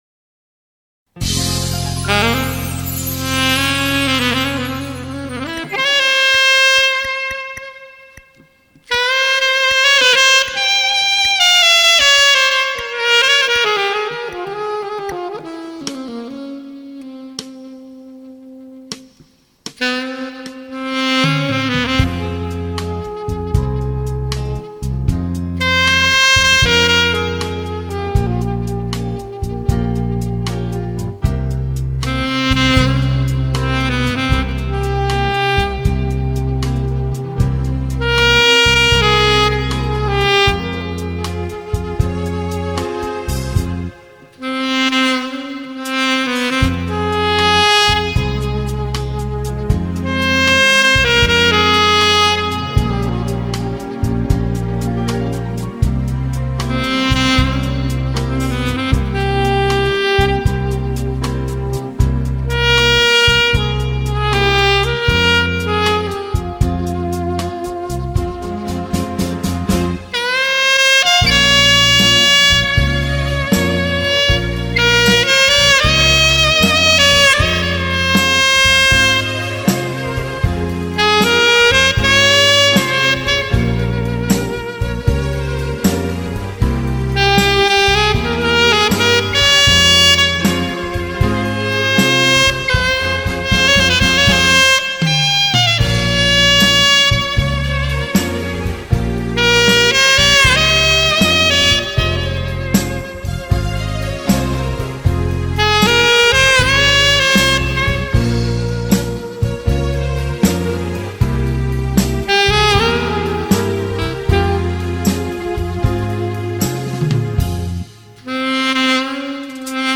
Аплодисменты убирать не стал.
А нельзя убрать аплодисменты и в начале с 4 по 15секунду какие то щелчки прослушиваются через наушники.....нельзя и их убрать, если это конечно возможно.